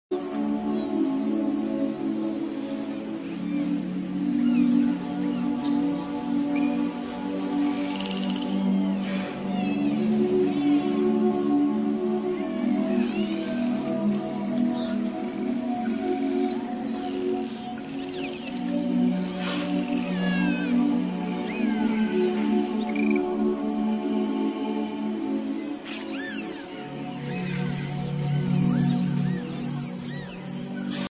Dolphins communicate through high frequency clicks sound effects free download
Dolphins communicate through high-frequency clicks and whistles—some reaching up to 150 kHz.